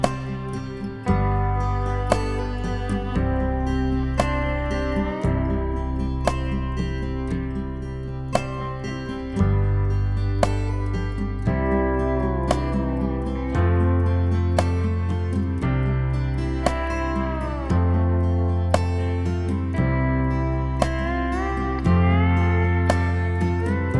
no Backing Vocals Country